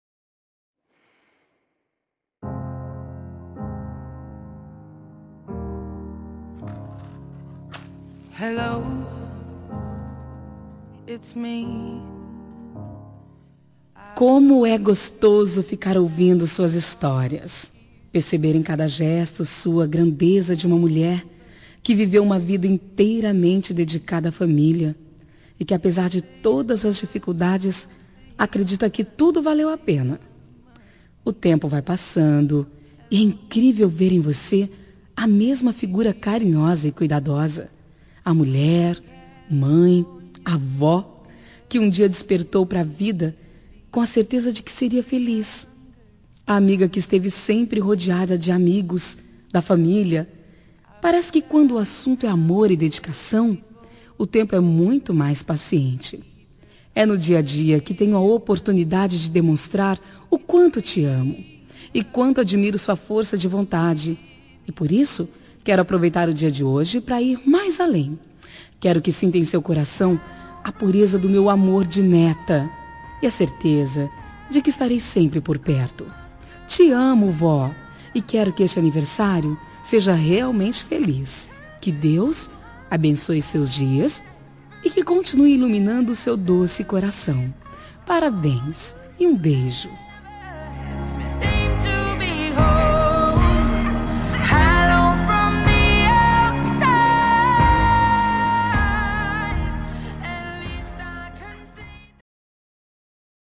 Telemensagem Aniversário de Avó – Voz Feminina – Cód: 2054